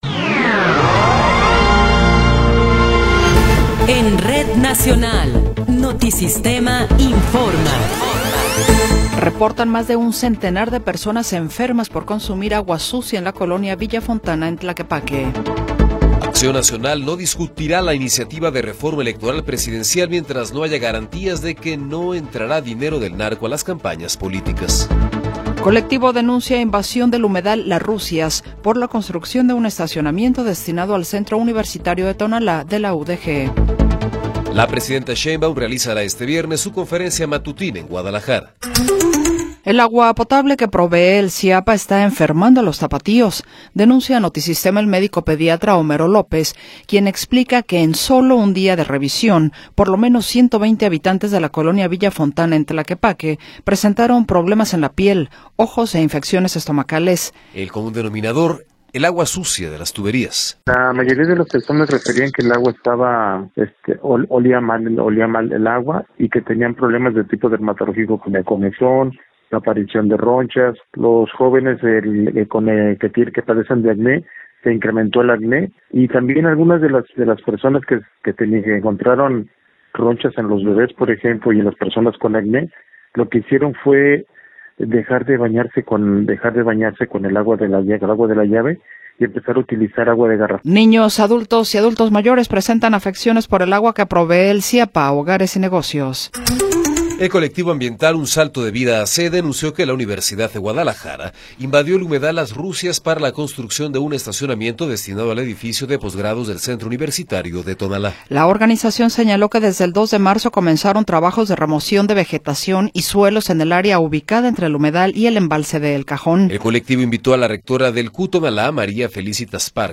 Noticiero 14 hrs. – 4 de Marzo de 2026